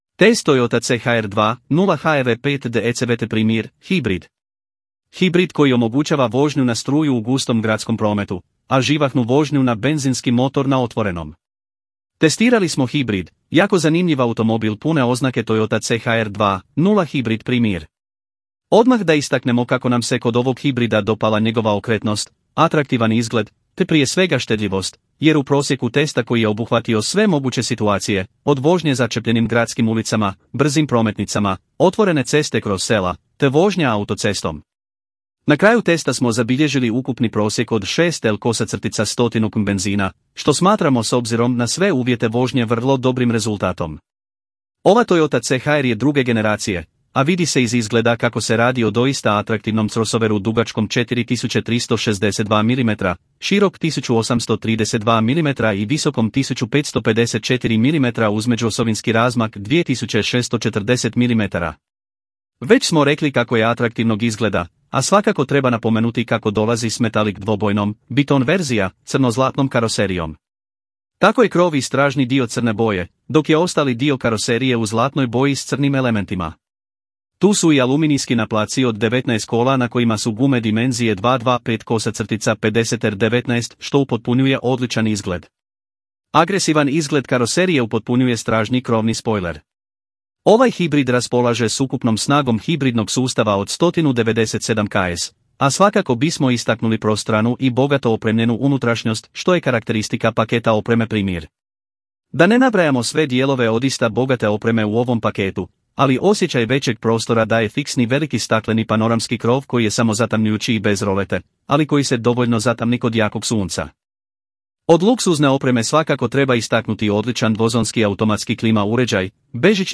POSLUŠAJTE AUDIO SNIMKU OBJAVLJENOG TEKSTA U ČLANKU (vrijeme 4:34 min) Testirali smo hibrid, jako zanimljiv automobil pune oznake Toyota C-HR 2.0 Hybrid Premiere.